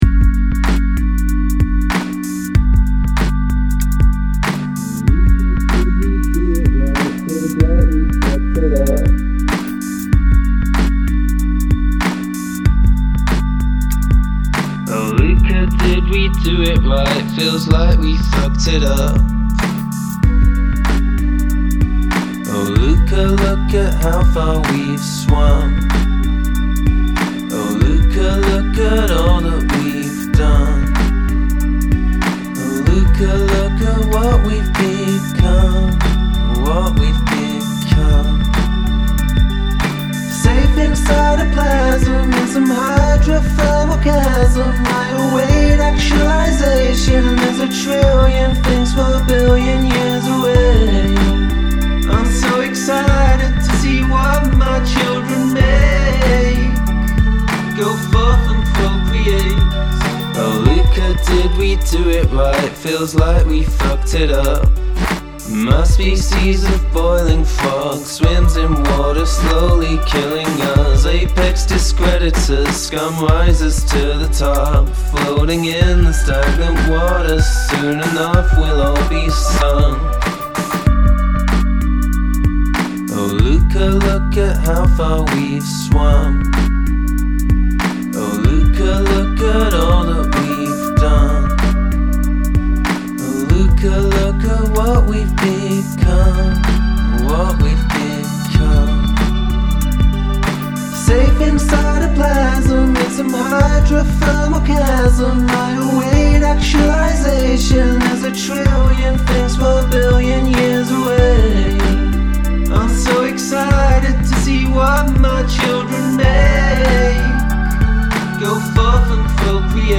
Cool groove. Super short song.